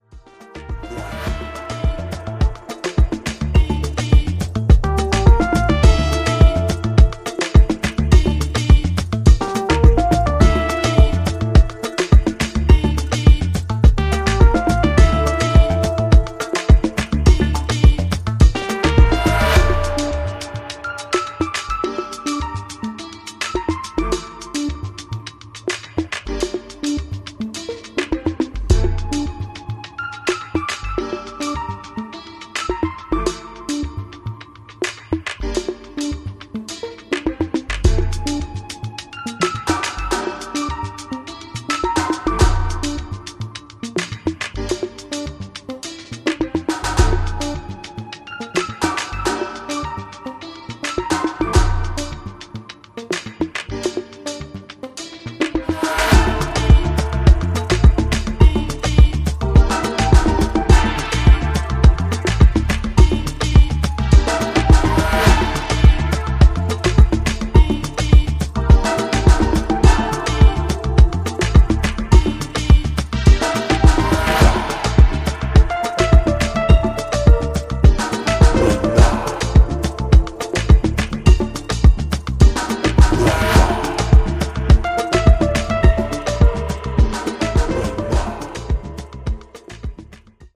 ジャンル(スタイル) DEEP HOUSE / AFRO